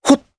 Evan-Vox_Jump_jp.wav